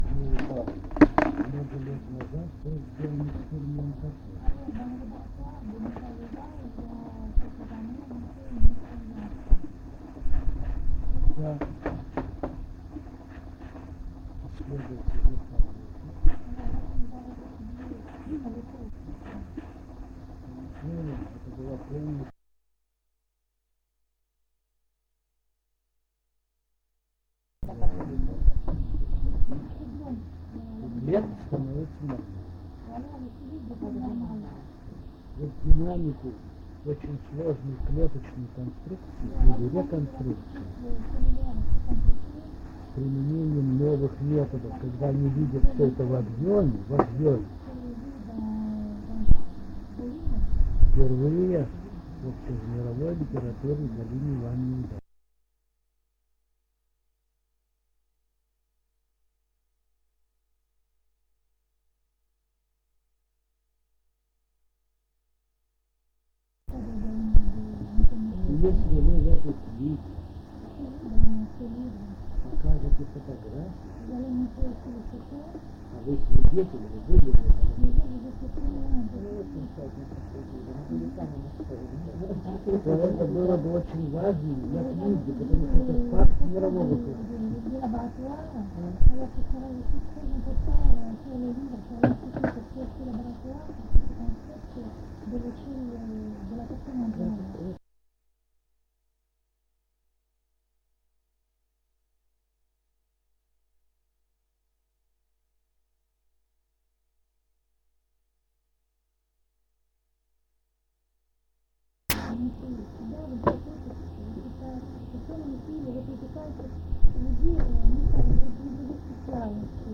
- Устная речь.